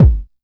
DJP_KICK_ (41).wav